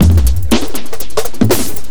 ELECTRO 08-L.wav